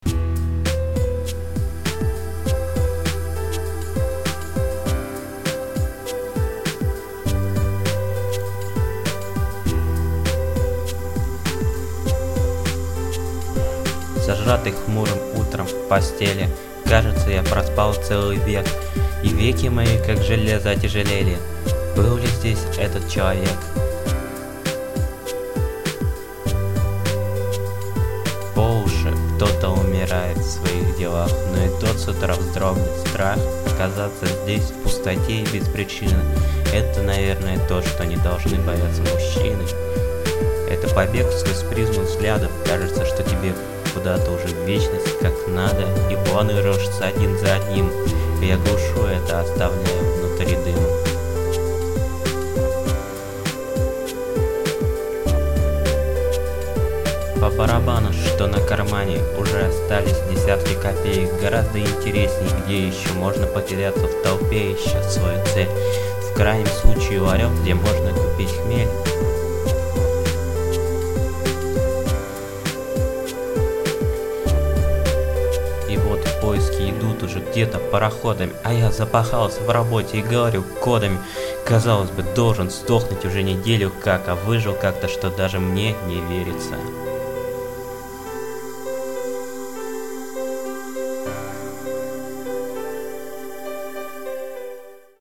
голос красивый smile
жизнено...)только тихо читаешь... и четче если б было бы лучше...
Очень понравились озвученные стихи, замечательно прочитано.
голос автора кстати на пленке friends